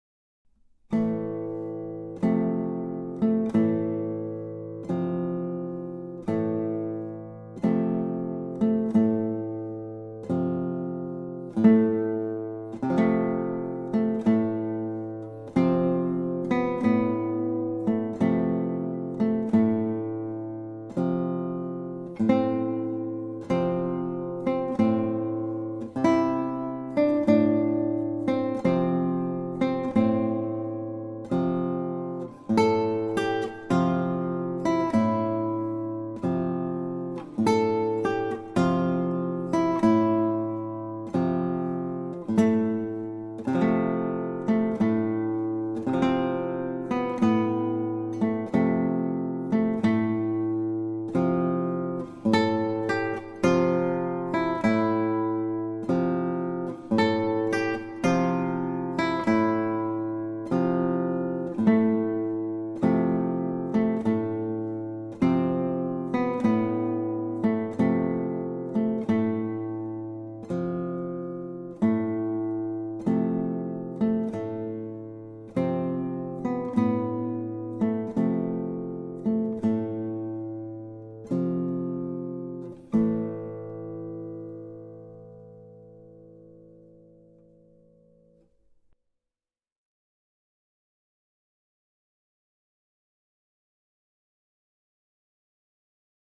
Грустная музыка для похорон на гитаре